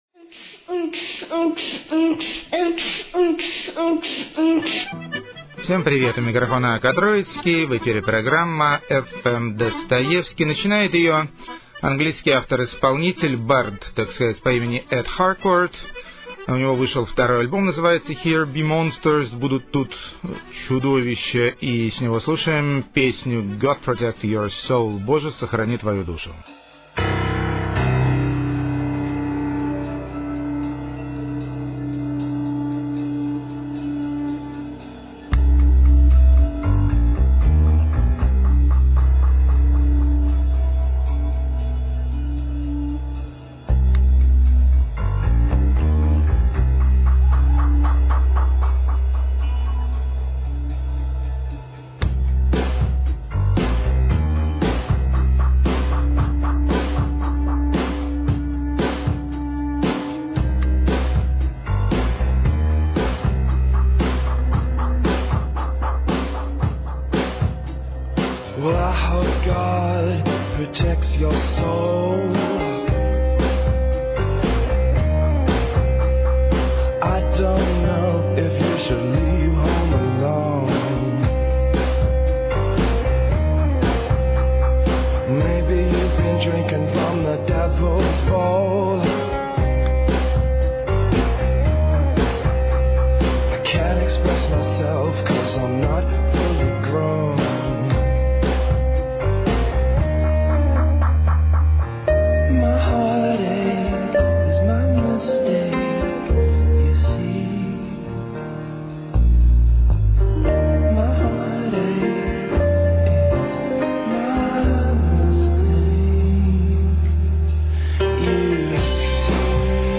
Классный Футуристический Гараж-транс.
Туареги! Пустынный Блюз! Супер!
Нежная Сюрная Меланхолия.